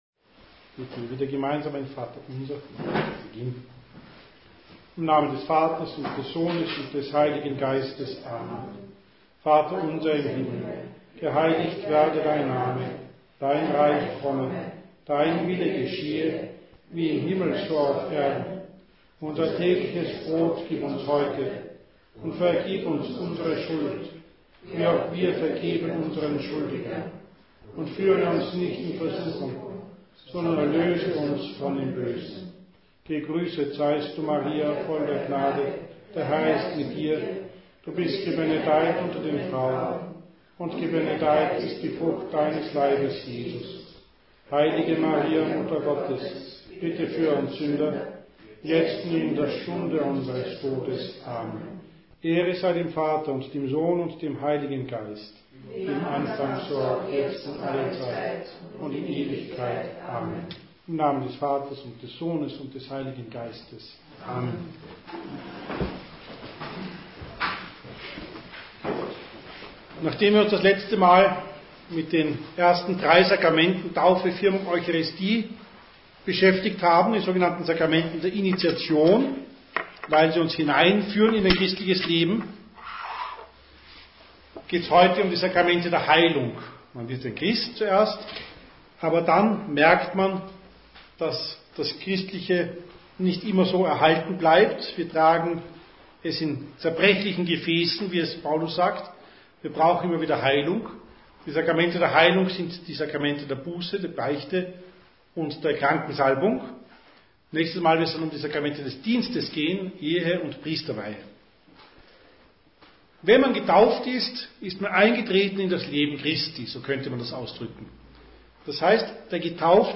Katechesen über den Katechismus zum Nachhören